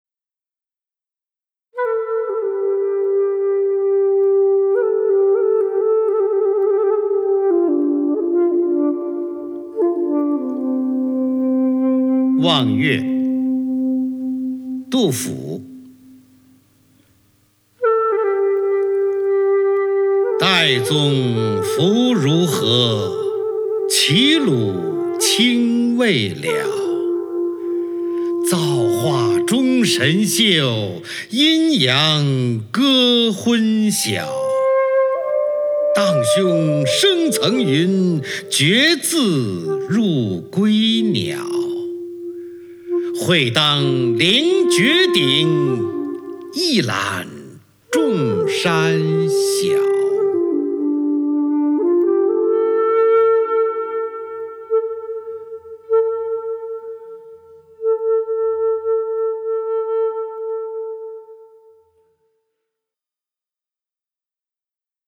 在名乐古曲中聆听著名朗诵艺术家抑扬顿挫、字正腔圆的朗诵，感受古诗词不朽的魅力。